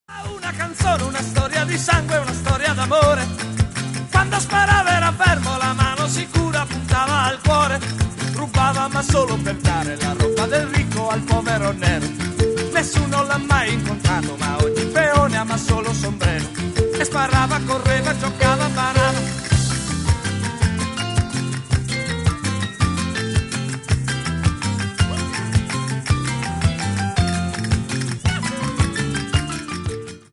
un'altra canzone dai sapori latini.